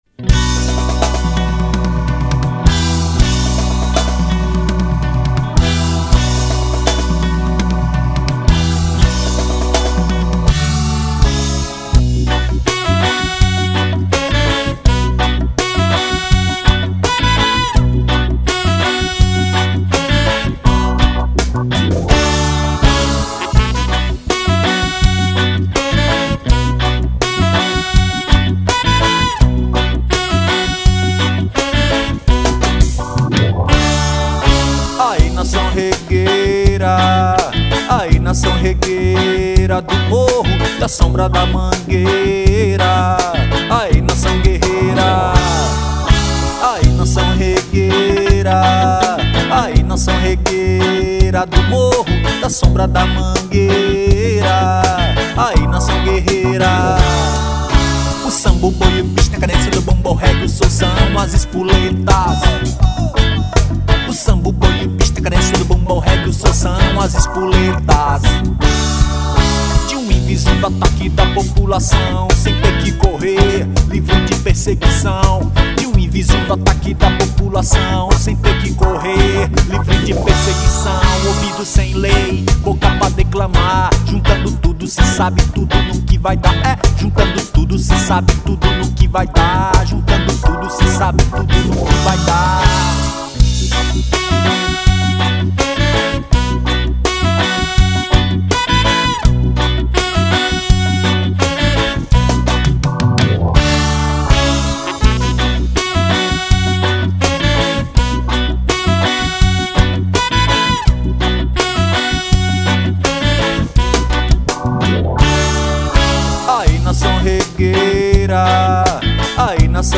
927   06:58:00   Faixa:     Reggae
Baixo Elétrico 6
Teclados
Sax Alto
Bateria